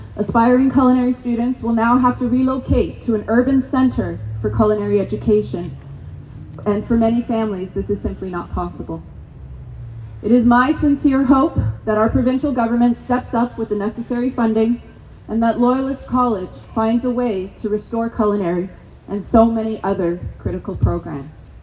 With chants of ‘our college, our future,’ a large number of faculty, support staff, students and union supporters rallied at Loyalist College Wednesday afternoon.
Professors from recently suspended programs made emotional addresses the crowd.